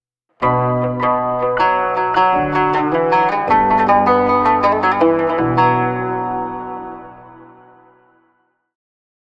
مستوحاة من مقام السيكا لتعكس تاريخ المدينة المنورة
الإشعارات والتنبيهاتنغمات قصيرة تُستخدم في الحرم الجامعي للتنبيهات والإعلانات
Oud version 2.wav